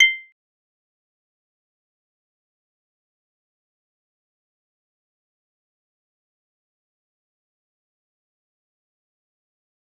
G_Kalimba-C8-mf.wav